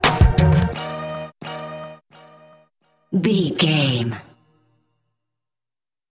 Aeolian/Minor
E♭
drum machine
synthesiser
percussion
hip hop
soul
Funk
acid jazz
confident
energetic
bouncy
funky
hard hitting